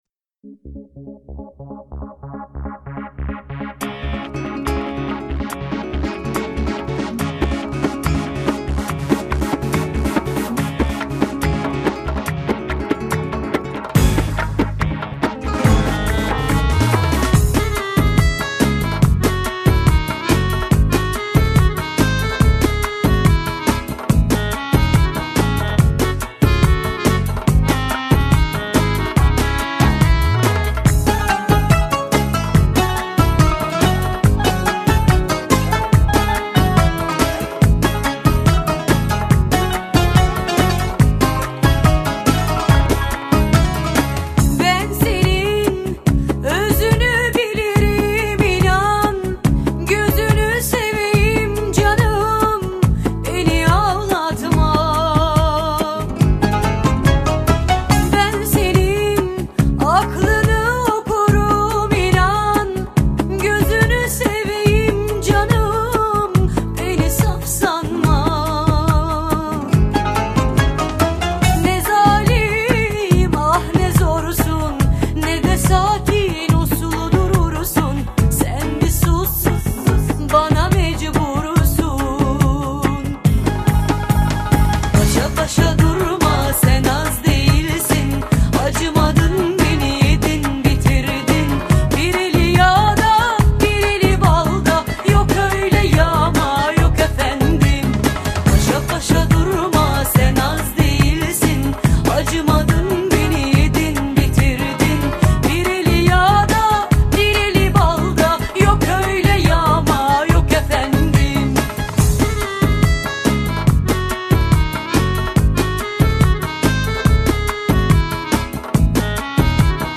Категория: Восточная музыка » Турецкие песни